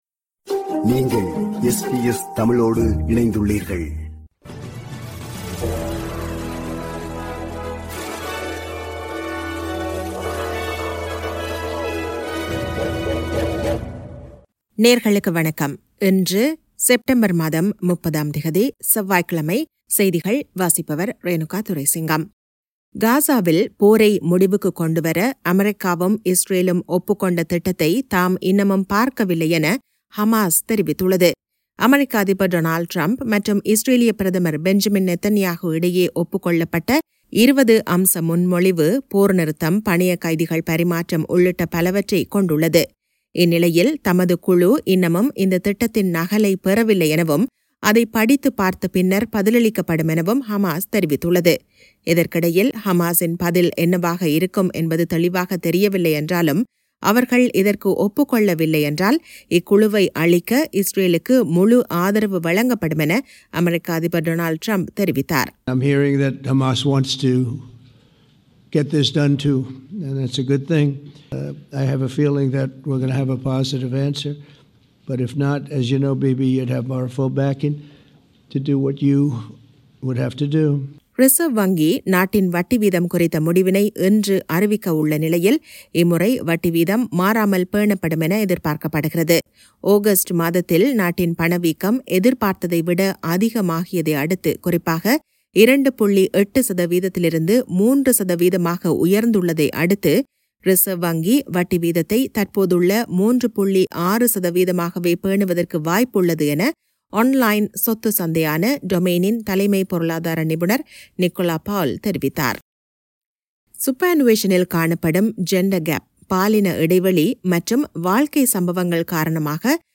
SBS தமிழ் ஒலிபரப்பின் இன்றைய (செவ்வாய்க்கிழமை 30/09/2025) செய்திகள்.